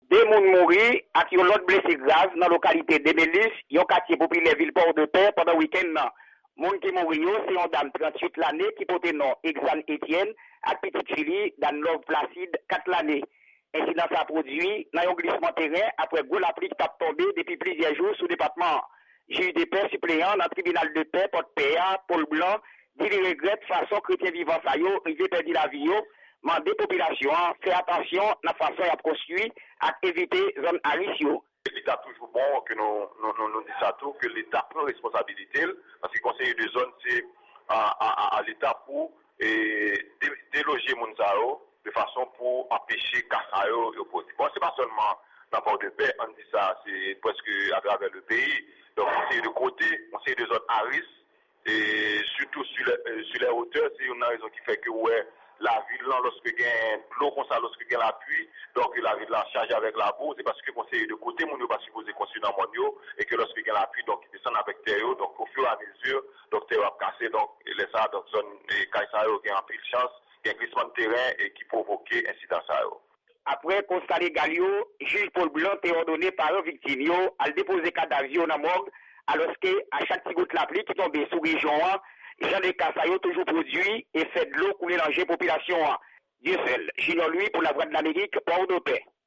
Yon repòtaj